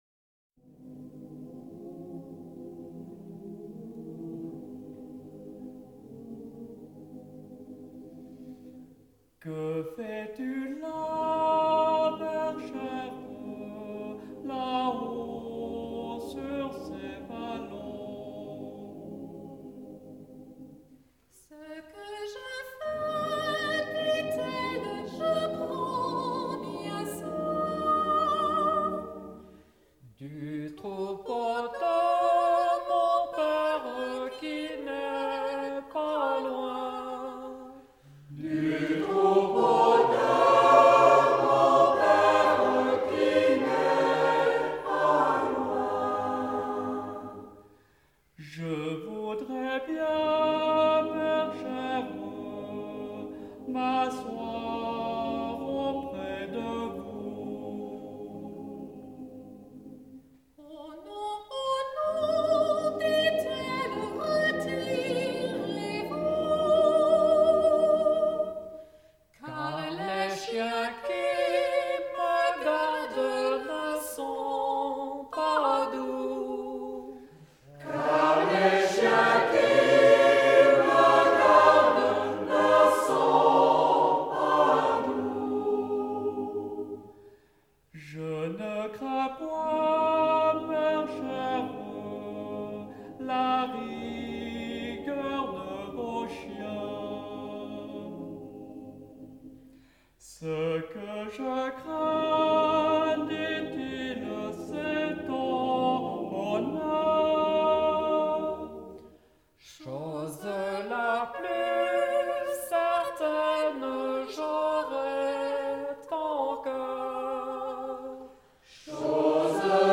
Choeur mixte de Morgins: La bergère – The sheperdess